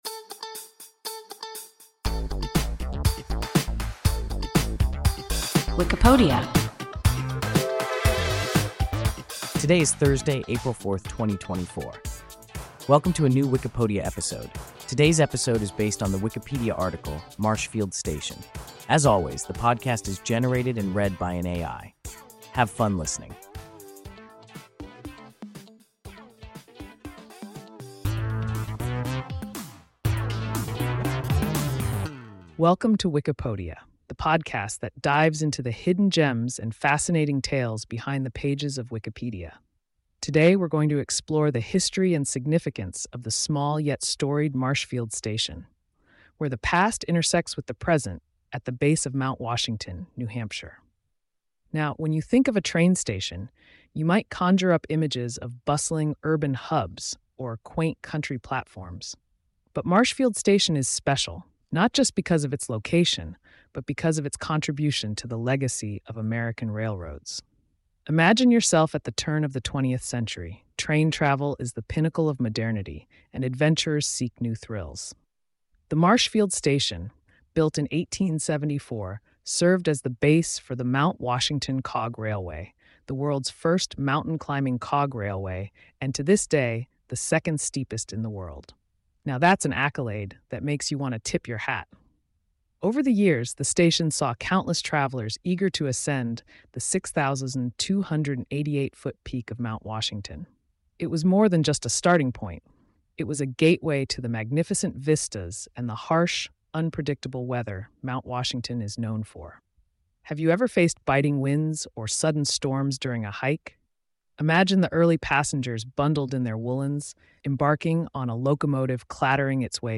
Marshfield station – WIKIPODIA – ein KI Podcast